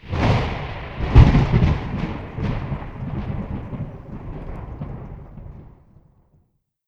tenkoku_thunder_medium02.wav